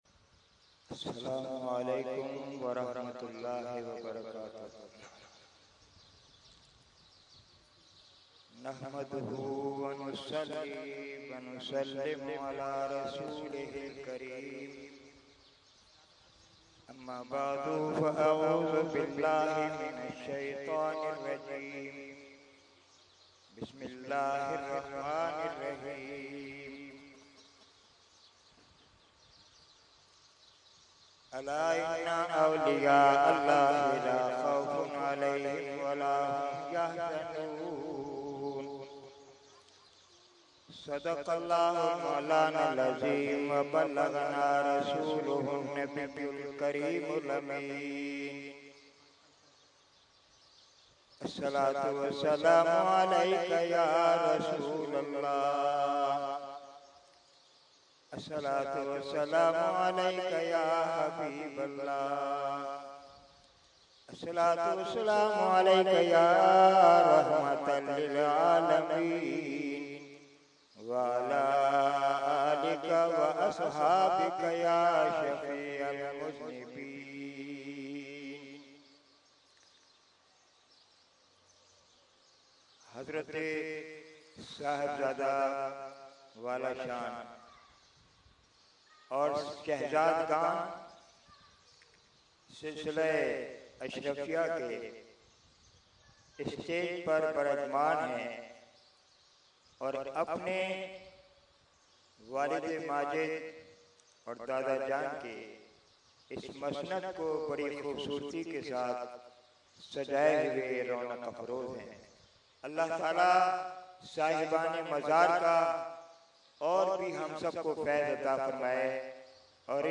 Category : Speech | Language : UrduEvent : Urs e Qutb e Rabbani 2013